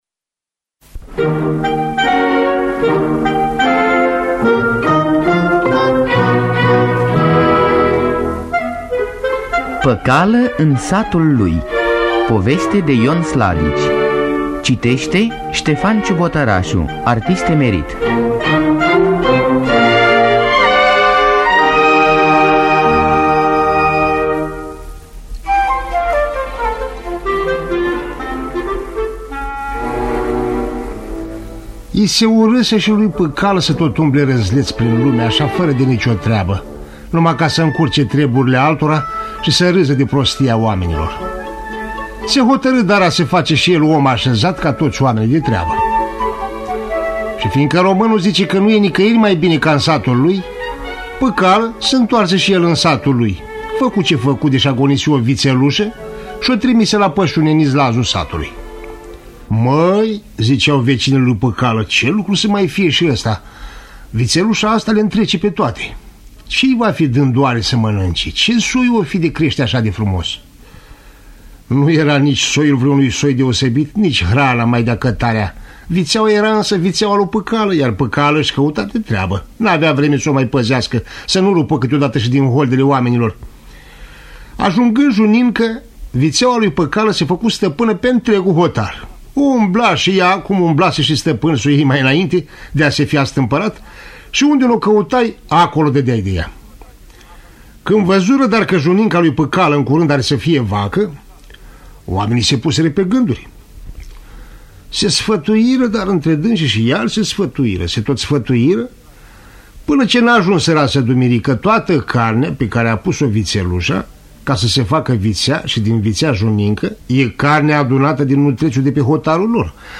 Interpretează Ştefan Ciubotăraşu.